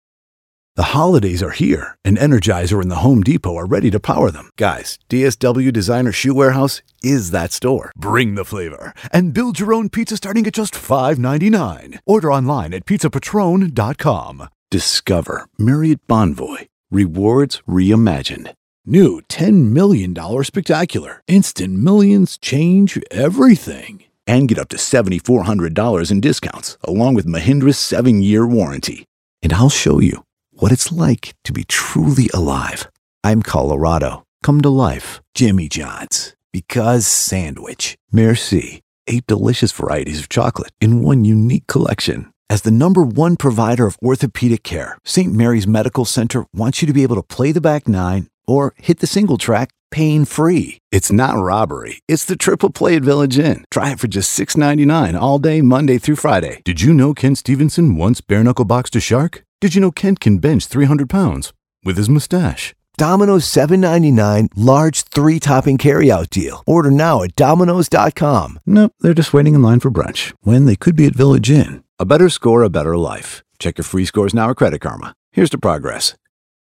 Male
Adult (30-50), Older Sound (50+)
Radio Commercials
A Dry Demo, No Music